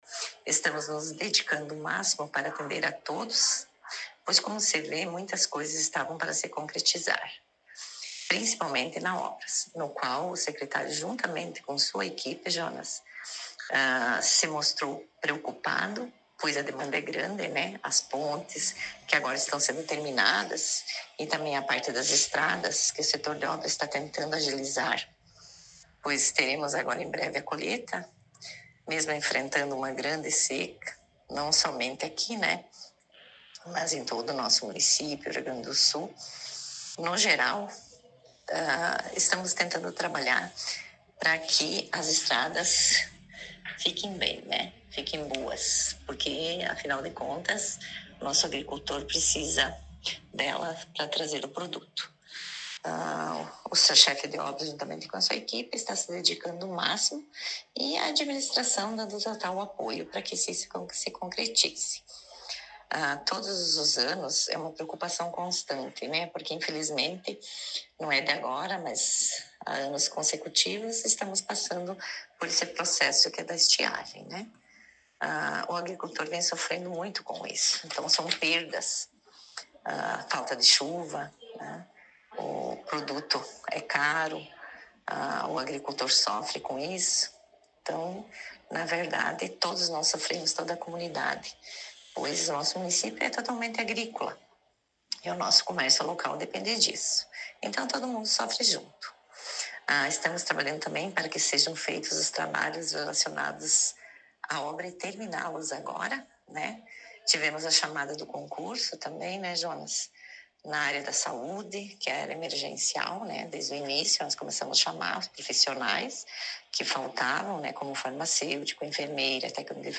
Vice-prefeita Marta Mino concedeu entrevista